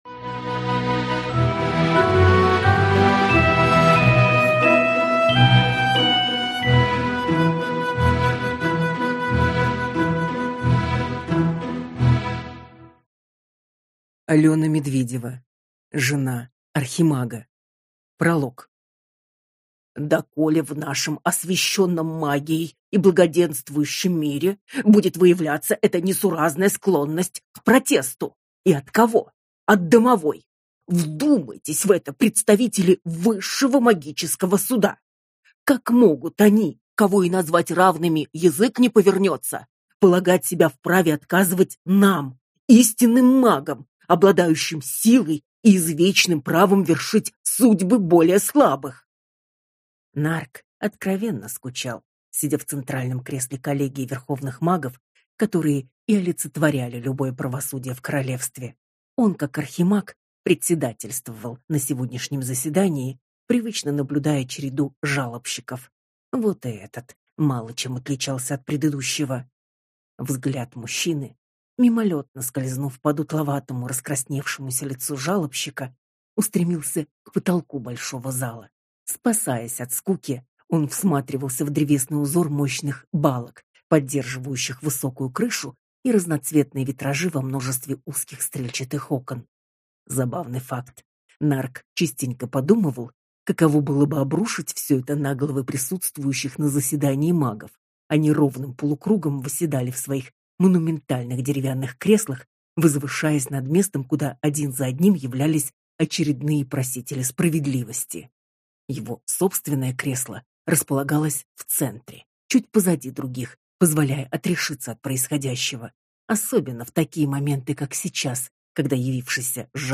Аудиокнига Жена архимага | Библиотека аудиокниг